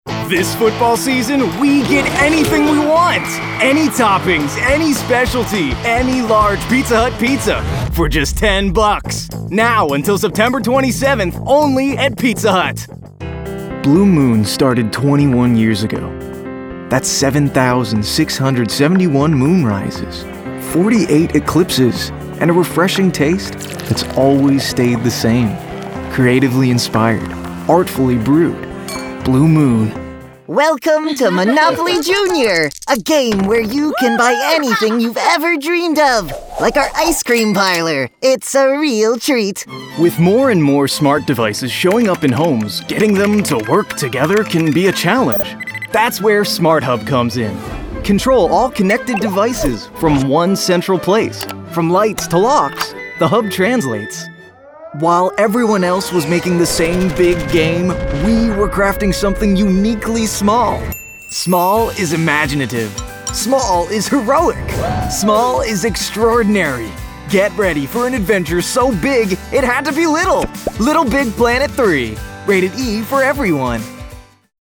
Teenager (13-17) | Yng Adult (18-29)